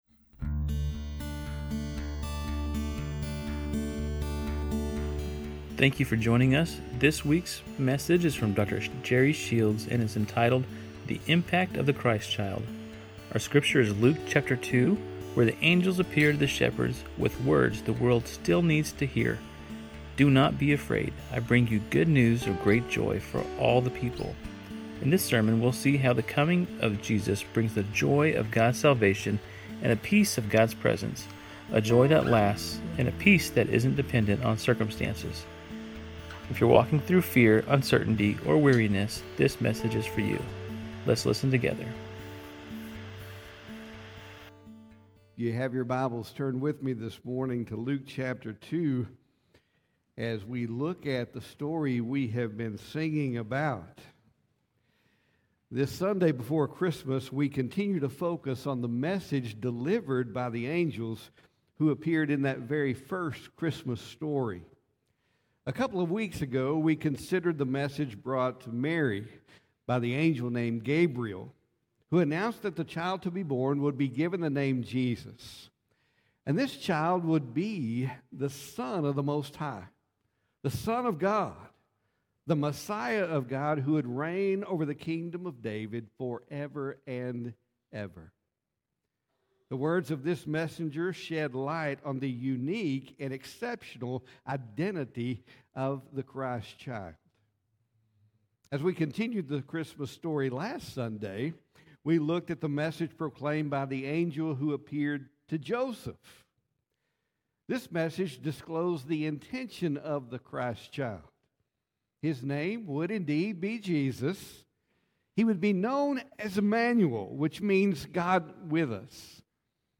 Christmas brings many temporary joys, but the gospel offers something deeper: the lasting joy of God’s salvation and the enduring peace of God’s presence. This sermon speaks to weary hearts facing fear, guilt, anxiety, grief, or uncertainty—and invites listeners to hear the angel’s words again: “Do not be afraid.”